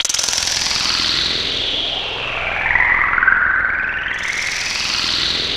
MOSQUITOS.wav